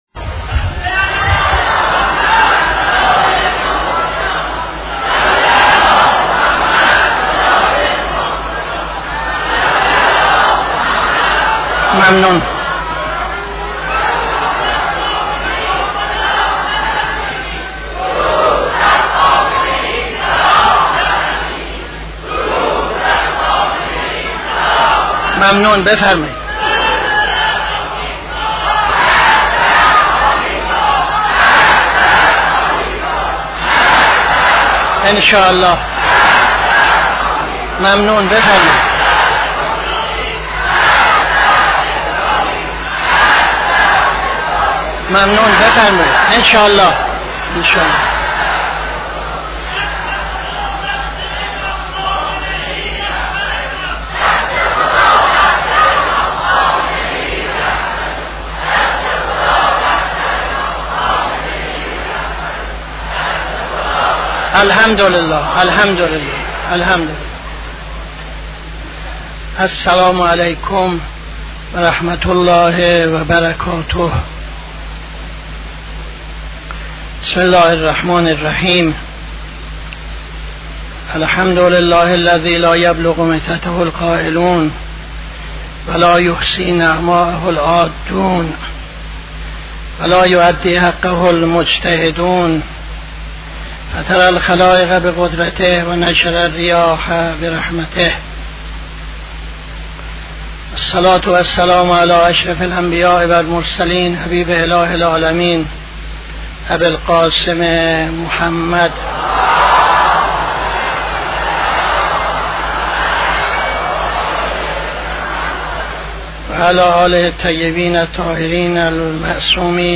خطبه اول نماز جمعه 14-01-77